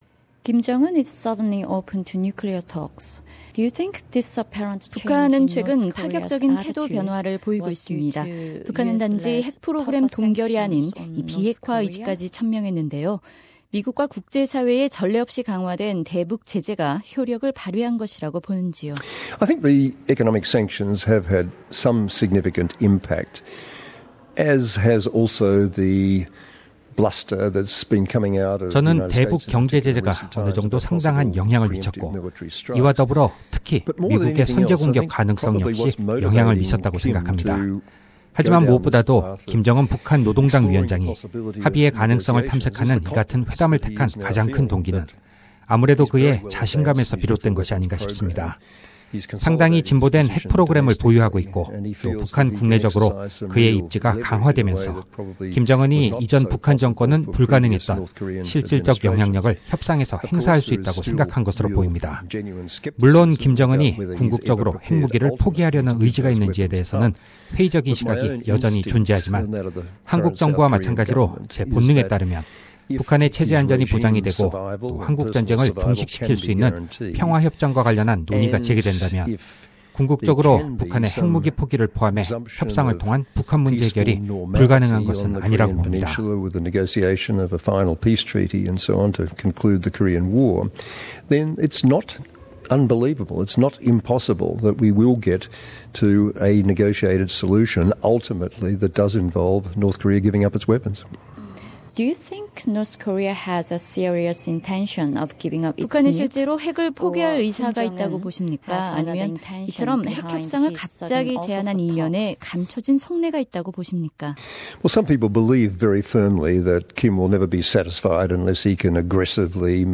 SBS Radio Korean program conducted an interview with Professor the Hon Gareth Evans, former Foreign Minister of Australia, about the denuclearization of the Korean Peninsula and Australia’s role in regional security.